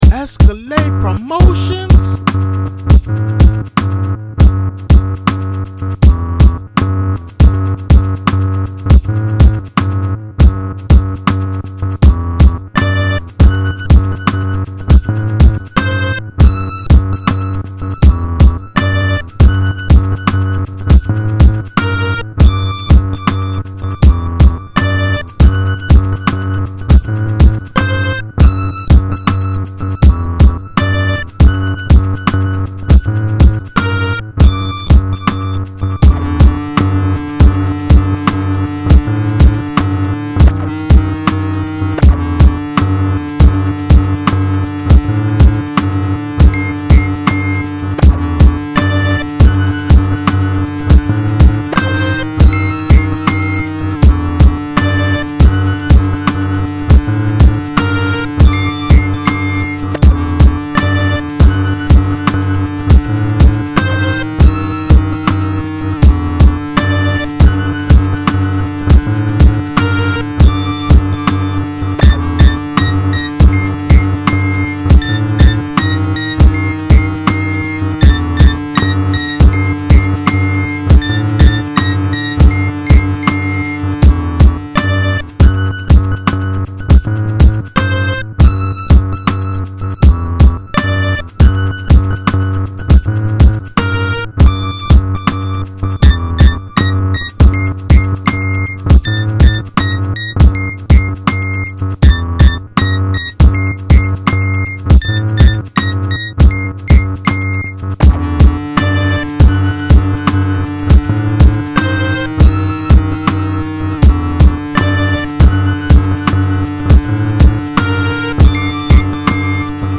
Hardcore BeatsForDaEmcee